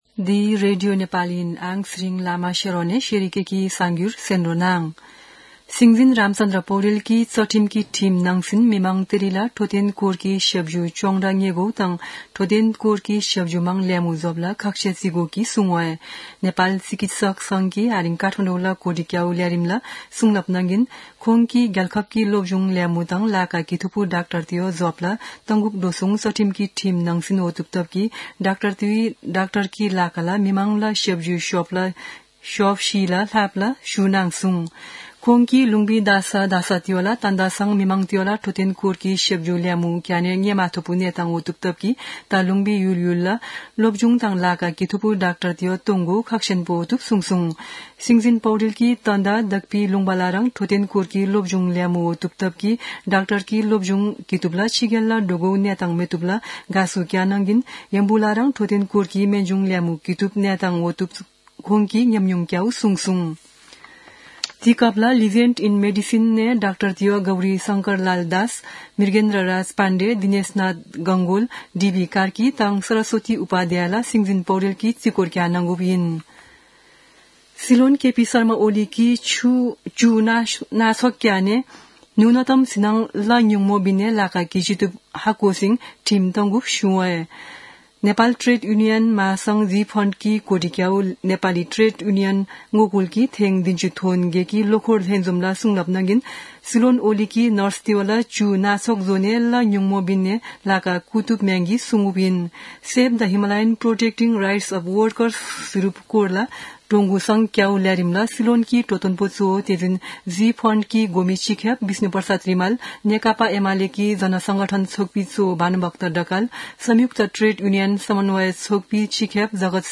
शेर्पा भाषाको समाचार : २१ फागुन , २०८१
sharpa-news.mp3